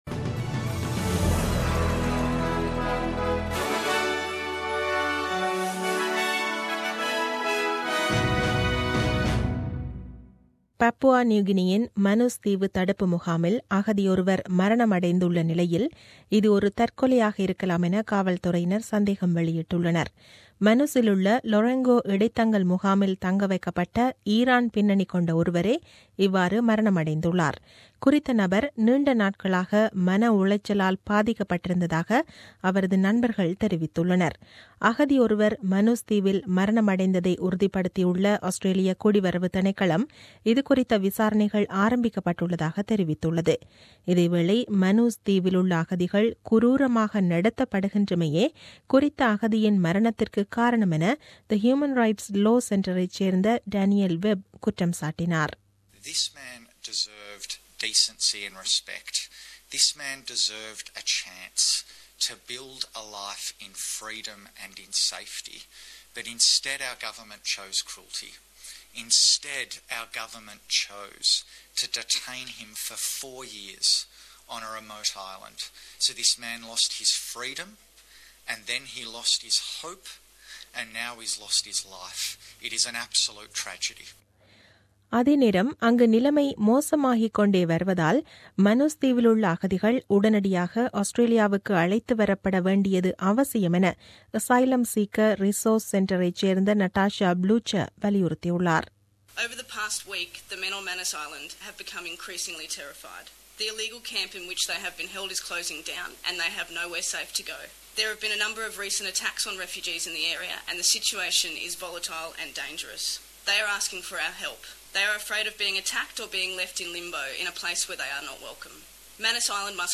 The news bulletin aired on 07 Aug 2017 at 8pm.